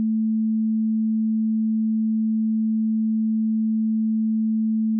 mp3_voice_note_sample.mp3